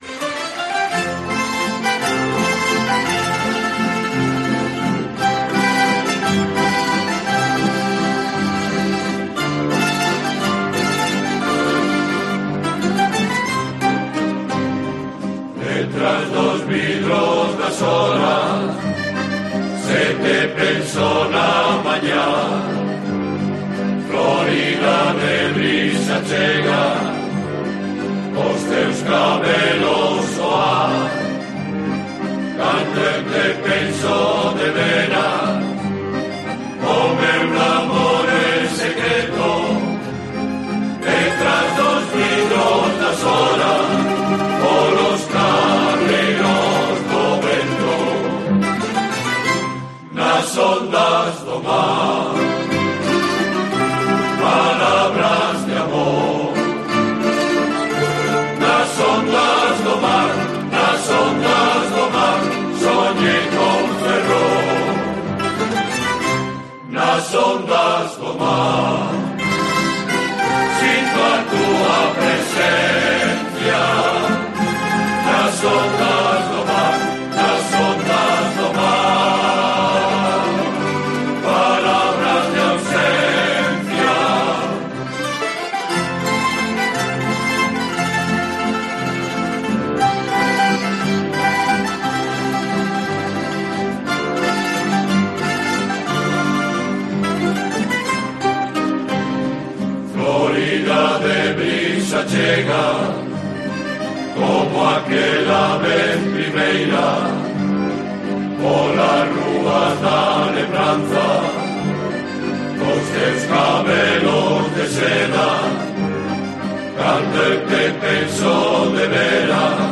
La canción "Nas ondas do mar" intepretada por la Rondalla Club de Campo